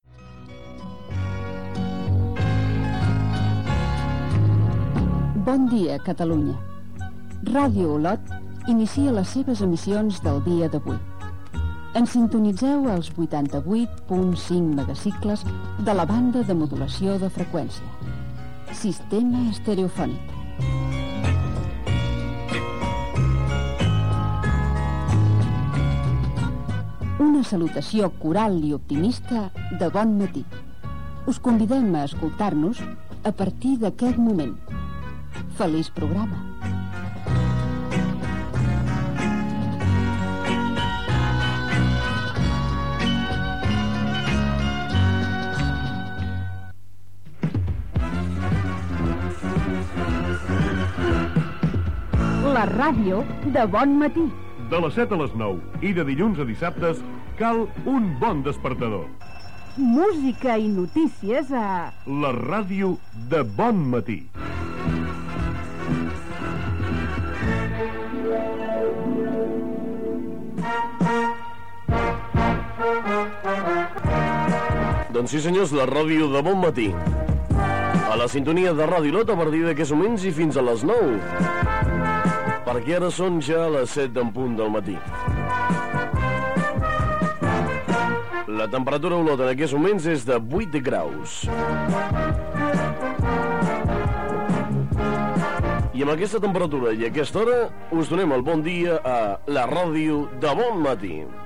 12f15fa47db4174c6b7d91bf72c167eeadb916d2.mp3 Títol Ràdio Olot Emissora Ràdio Olot Titularitat Privada local Nom programa La ràdio de bon matí Descripció Inici d'emissió i del programa "La ràdio de bon matí". Gènere radiofònic Entreteniment